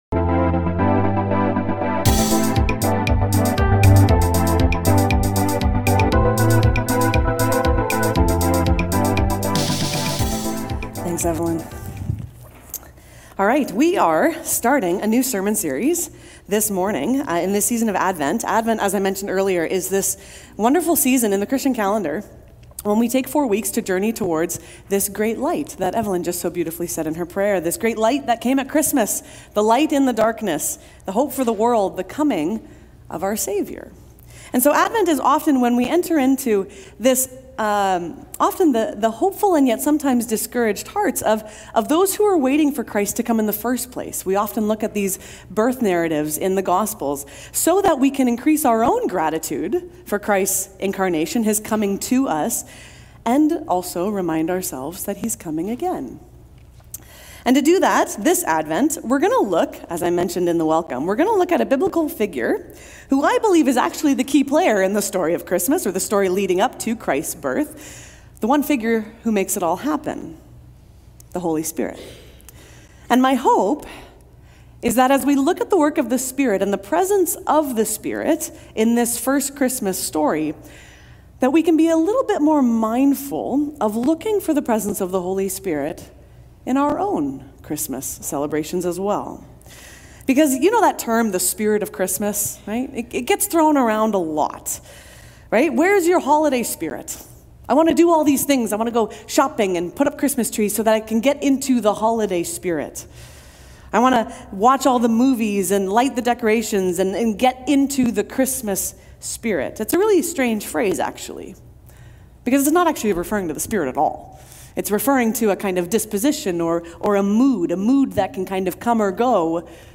Willoughby Church Sermons | Willoughby Christian Reformed Church